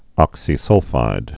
(ŏksē-sŭlfīd)